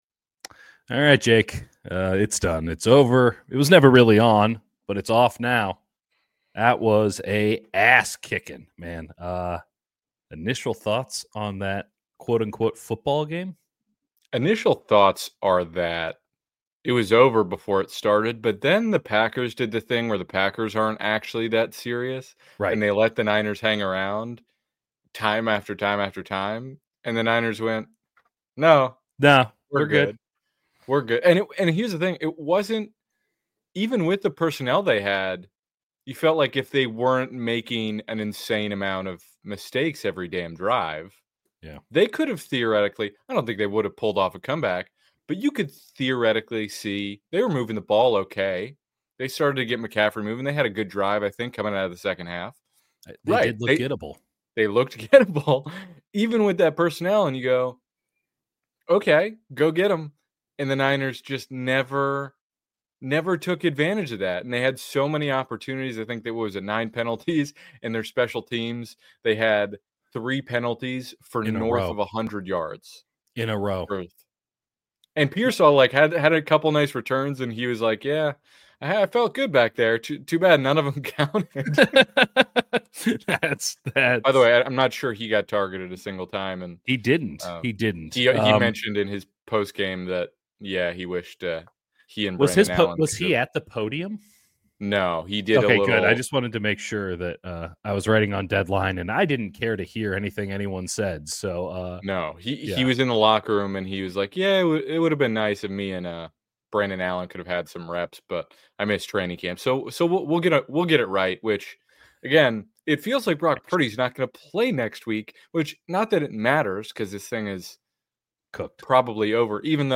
A New Low | 49ers-Packers LIVE Reaction